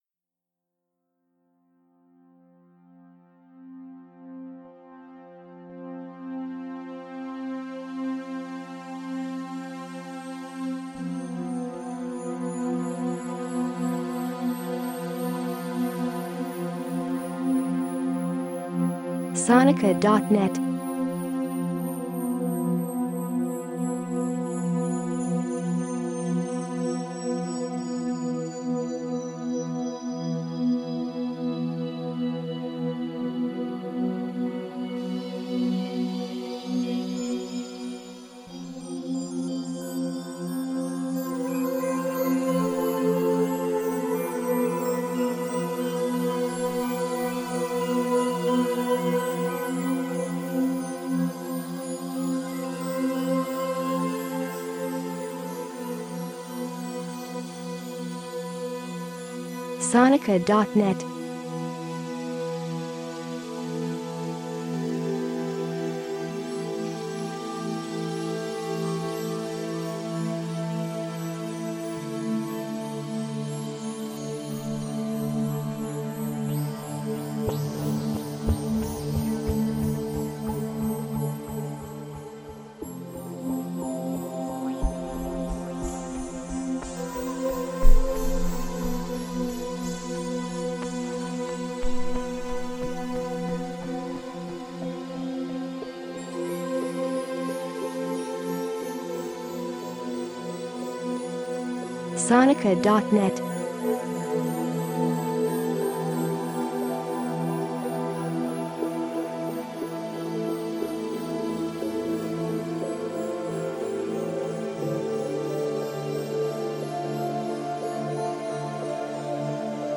A sonic journey through emotional landscapes.